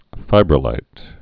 (fībrə-līt)